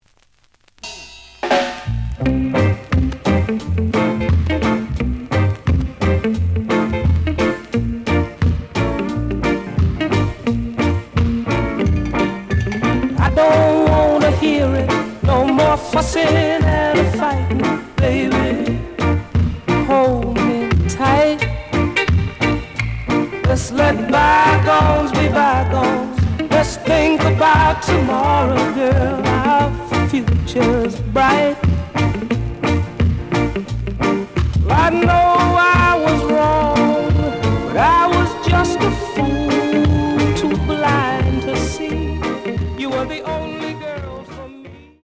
The audio sample is recorded from the actual item.
●Genre: Rock Steady